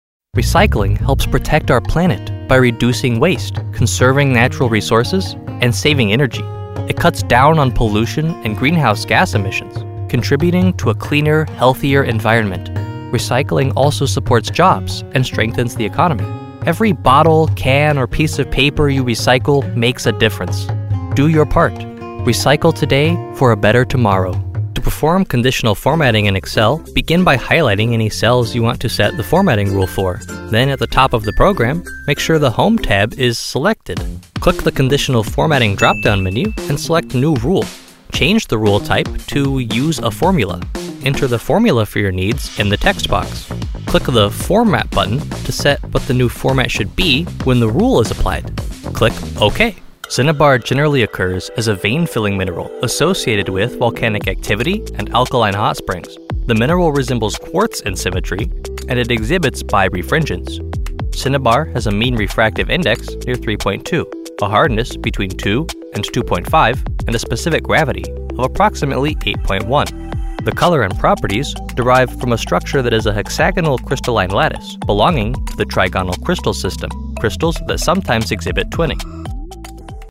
With the flexibility to record in my own fully equipped home studio, I’m committed to bringing the right voice to your project.
Narration Demos
Narration-Demos.mp3